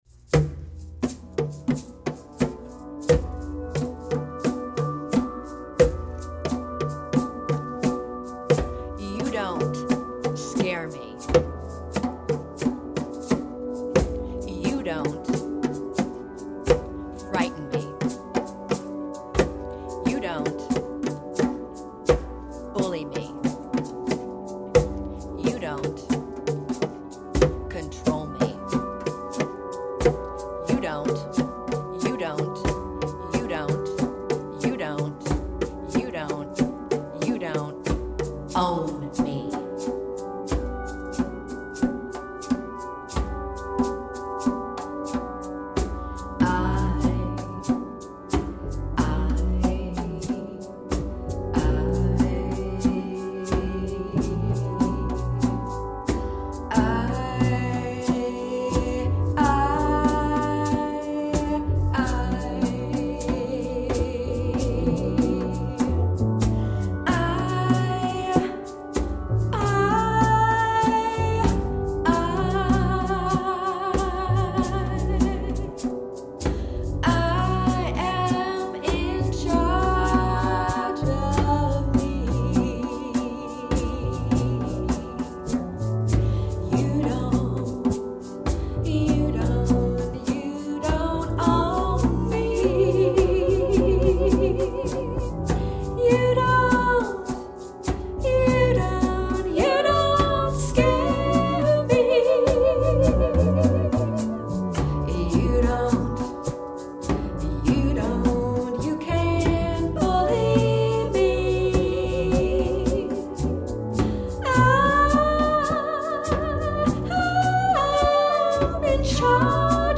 Vocals
Drum
Rattle
Guitar, Bass, and Soundscape